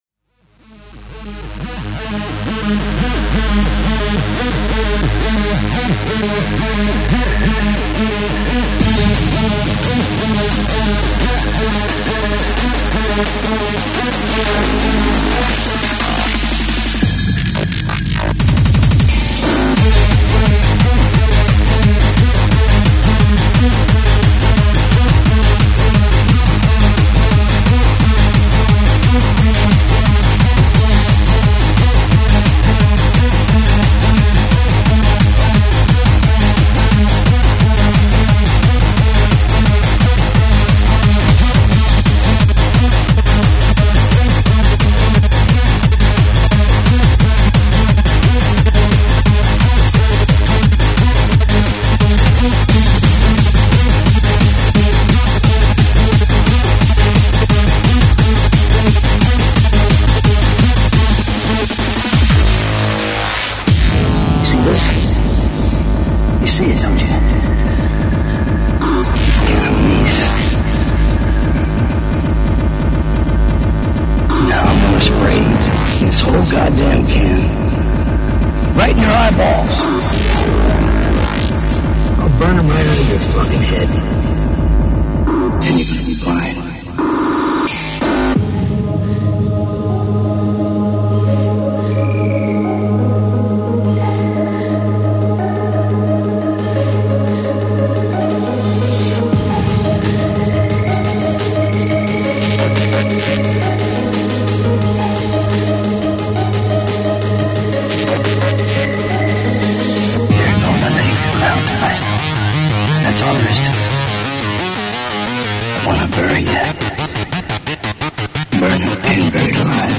Freeform/Hardcore